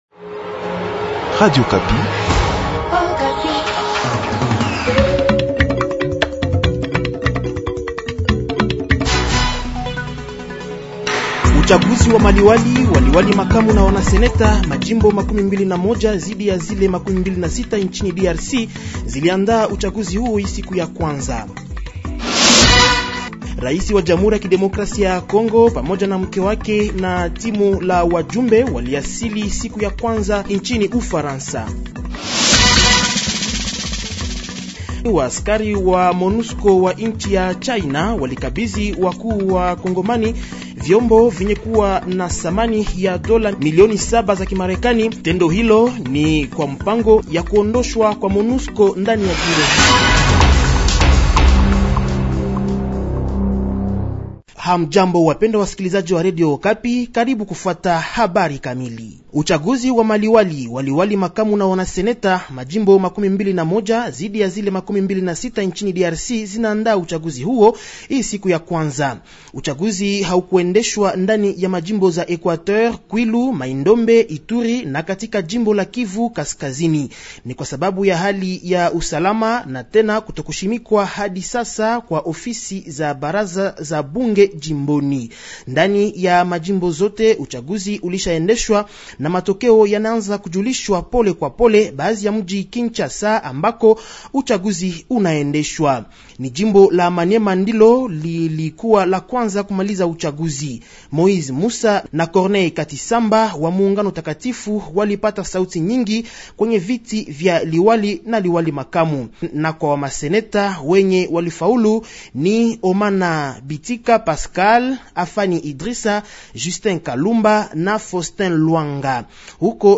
JOURNAL SWAHILI DU MARDI 30 AVRIL 2024